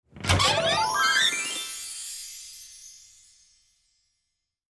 Treasure Chest Opening.wav